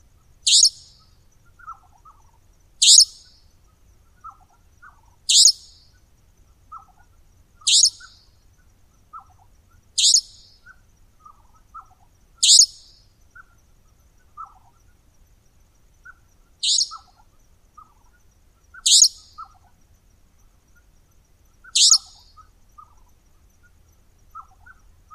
Вы можете слушать онлайн или скачать трели, крики и другие голосовые проявления козодоя в формате mp3.
Звуки козодоя в ночи